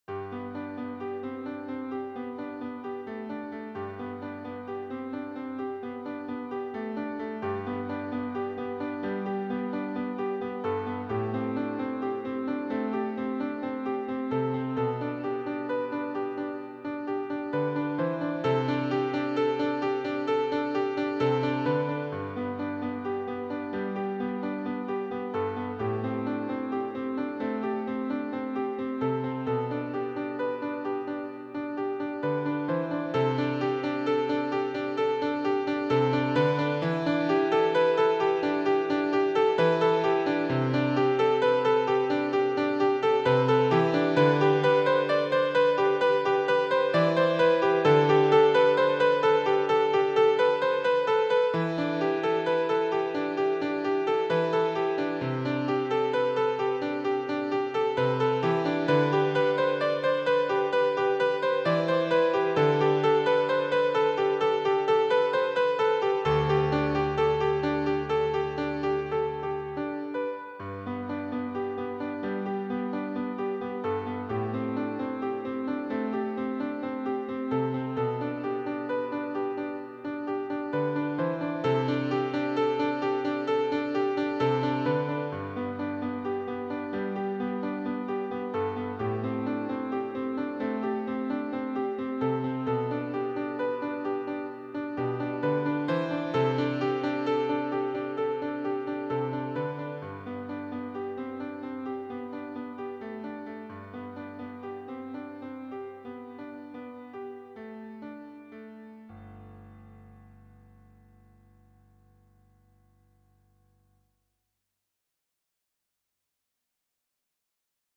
6 Easy piano pieces